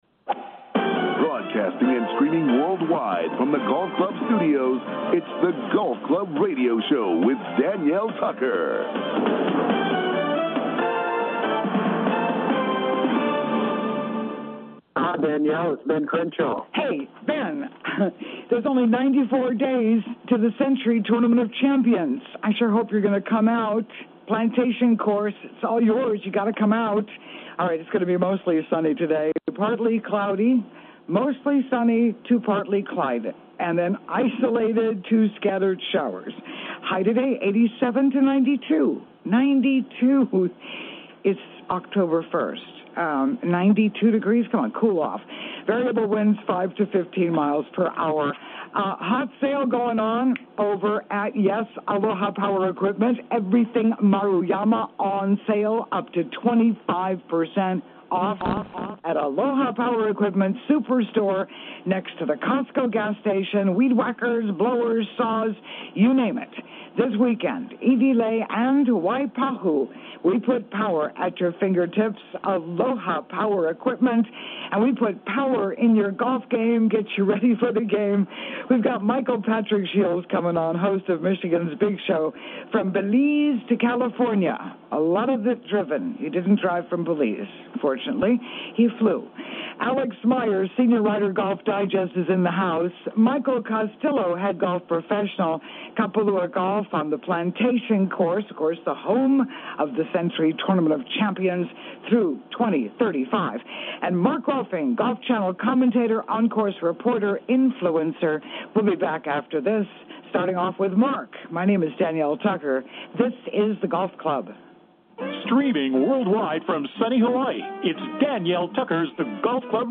COMING TO YOU LIVE FROM THE GOLF CLUB STUDIOS ON LOVELY OAHU
Mark Rolfing Golf Course Commentator